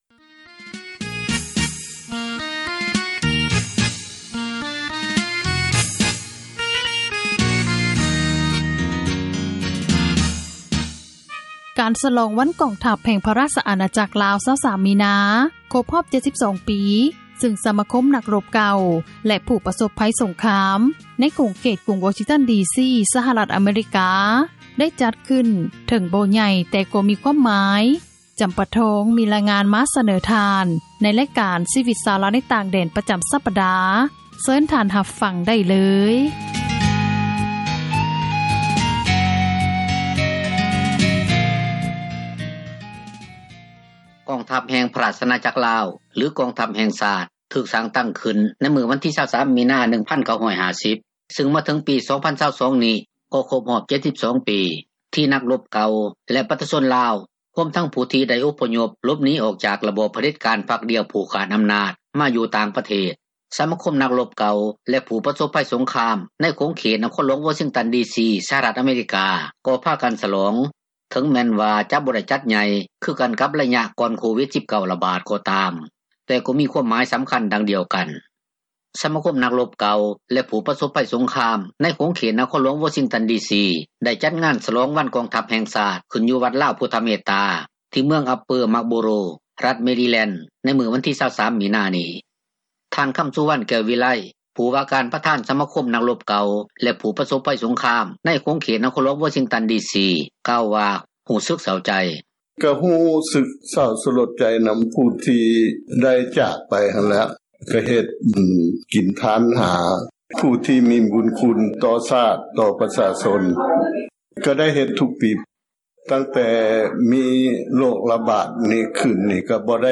ສະມາຄົມນັກຣົບເກົ່າແລະຜູ້ປະສົບໄພສົງຄາມໃນຂົງເຂດນະຄອນຫລວງວໍຊິງຕັນ ດີຊີ ໄດ້ຈັດງານສລອງວັນກອງທັບແຫ່ງຊາດ ຂຶ້ນຢູ່ວັດລາວພຸດທະເມດຕາ ທີ່ເມືອງ Upper Marlboro ຣັຖ Maryland ໃນມື້ວັນທີ 23 ມິນານີ້.